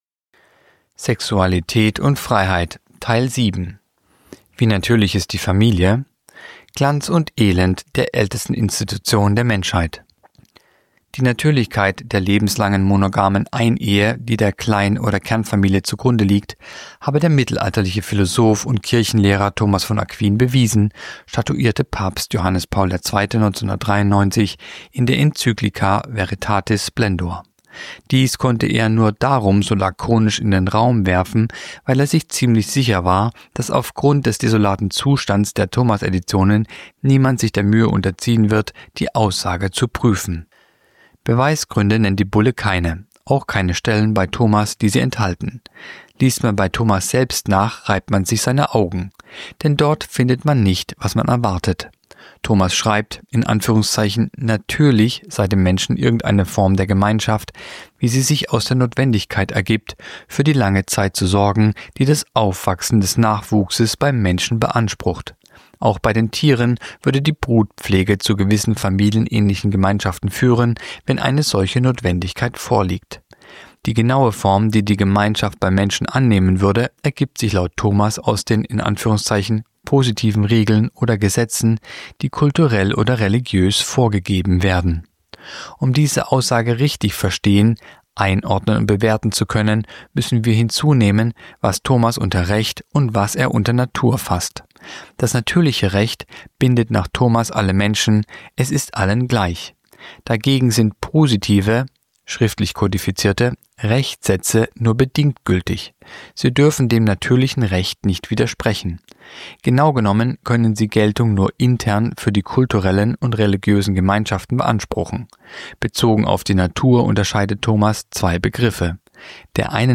Kolumne der Woche (Radio)Wie natürlich ist die Familie?